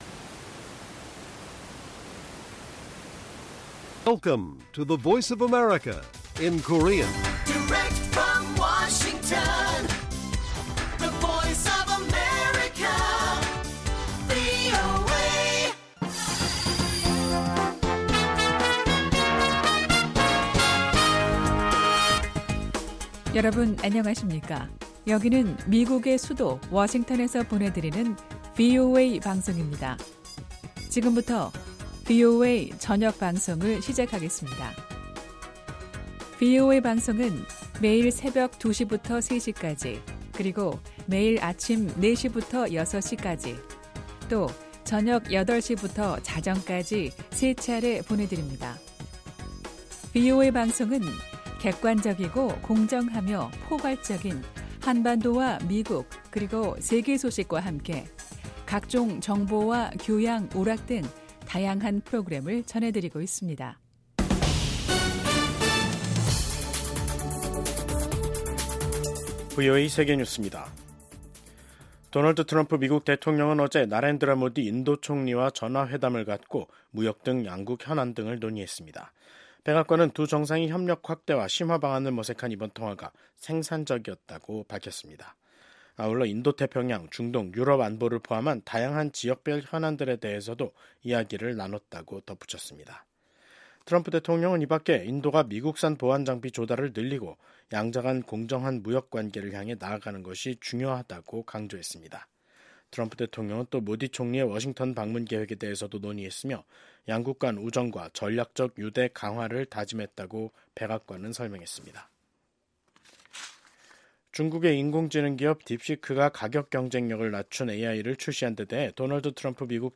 VOA 한국어 간판 뉴스 프로그램 '뉴스 투데이', 2025년 1월 28일 1부 방송입니다. 한국과 쿠바 두 나라는 수교 11개월만에 대사관 개설과 대사 부임 절차를 마무리했습니다. 트럼프 대통령이 북한을 ‘핵 보유국’이라고 지칭한 이후 한국 내에서 커지고 있는 자체 핵무장론과 관련해 미국 전문가들은 미한동맹과 역내 안정에 부정적인 영향을 미칠 것이라고 전망했습니다.